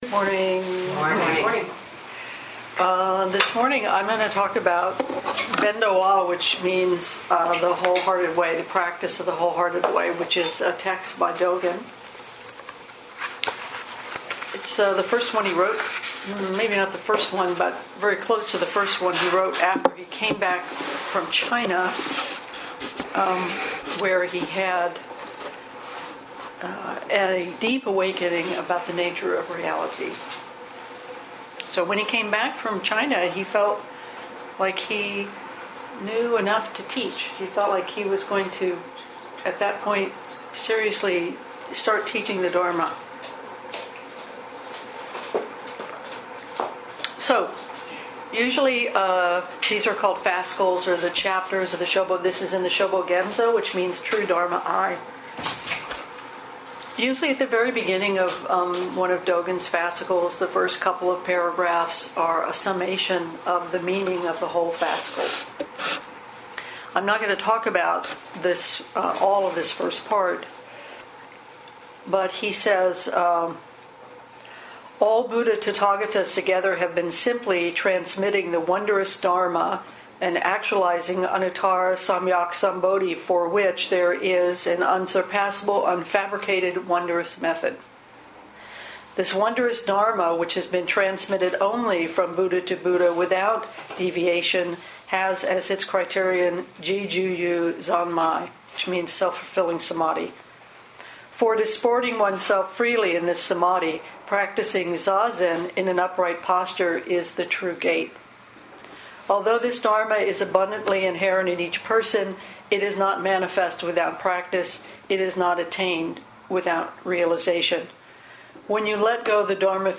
2014 in Dharma Talks